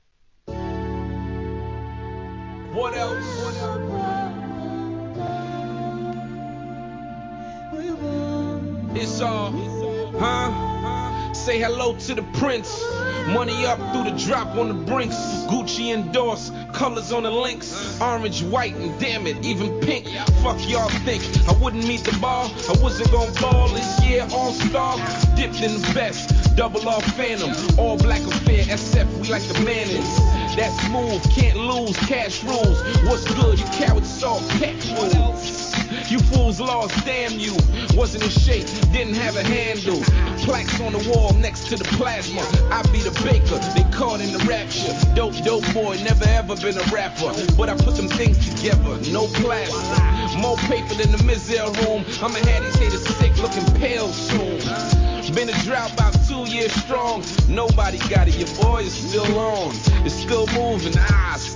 HIP HOP/R&B
透き通るようなヴォーカルLOOPにグッときます!!